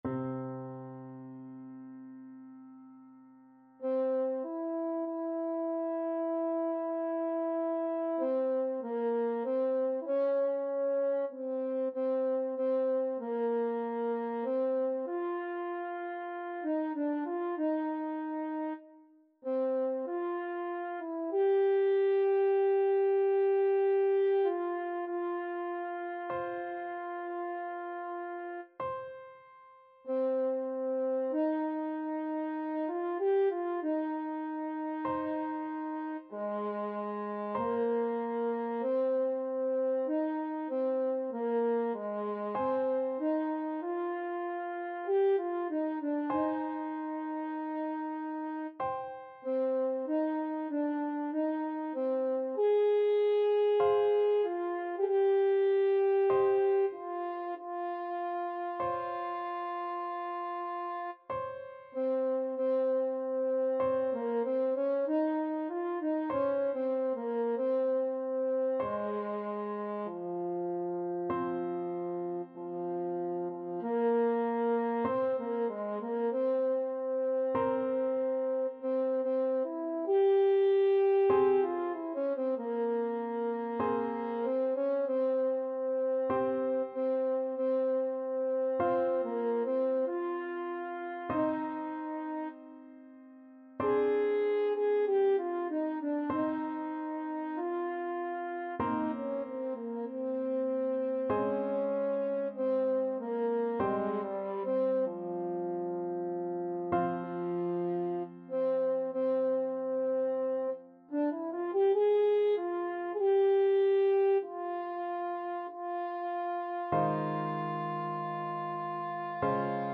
Lent =48
Classical (View more Classical French Horn Music)